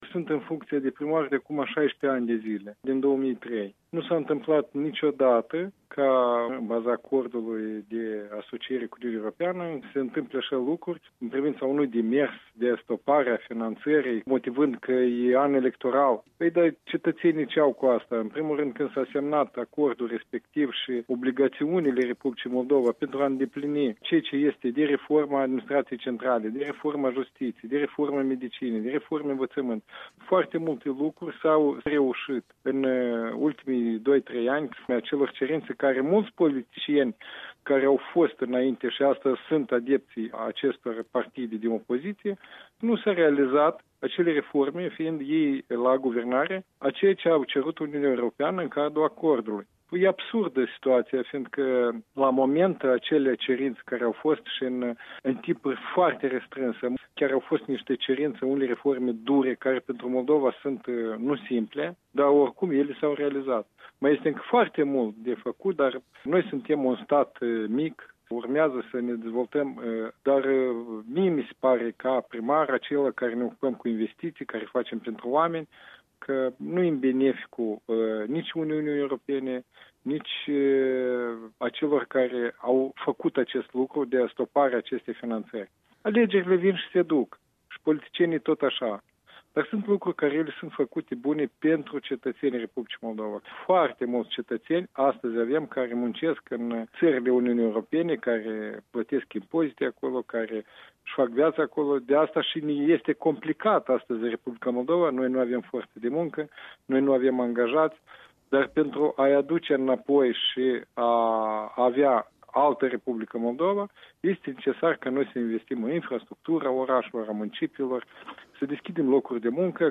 Un interviu cu primarul localității Hîncești, vicepreședinte PD și explicațiile sale despre sistarea asistenței financiare a UE pentru Moldova.